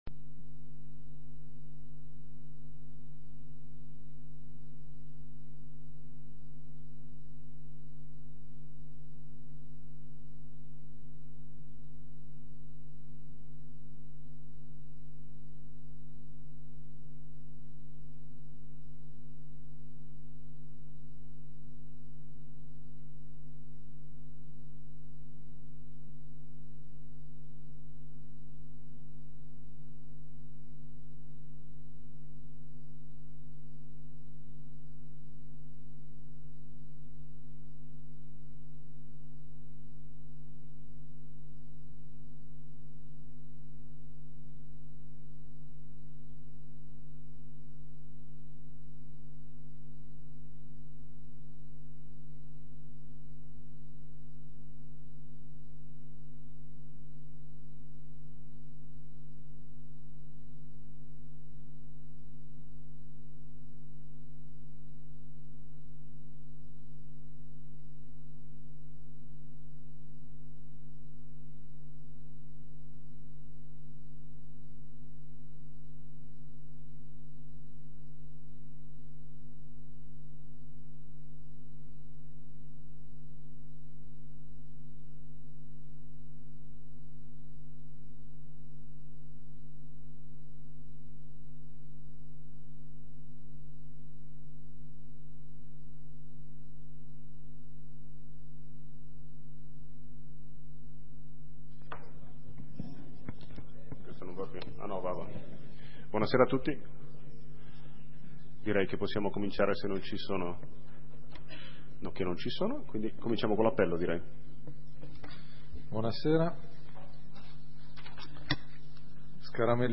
Seduta consiglio comunale del 27 ottobre 2023 - Comune di Sondrio